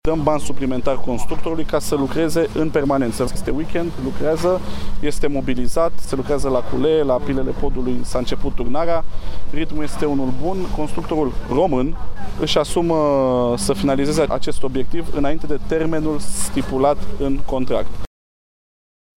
Ministrul Transporturilor a mai declarat că în cel mai scurt timp consortiul turco-chinez va demara lucrările și pentru loturile de autostradă ce vor lega Ploieștiul de Brașov, adică în această vară: